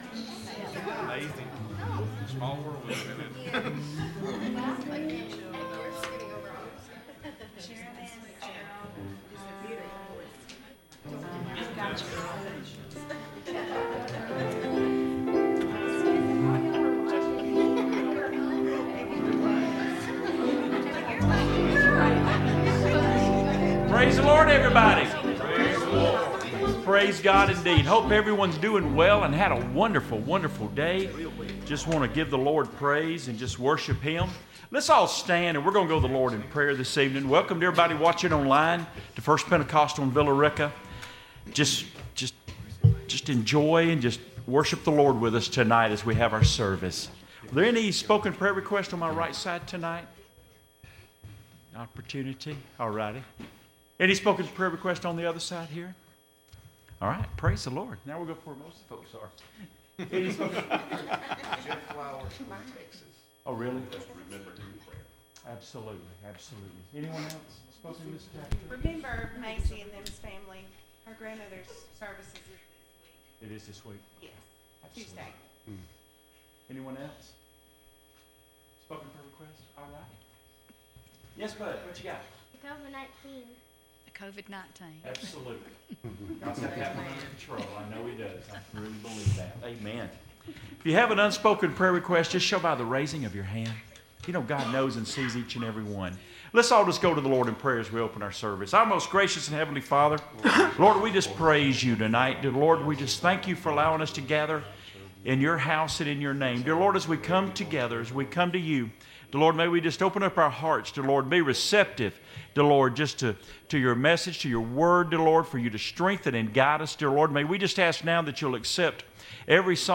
A Night of Singing and Worship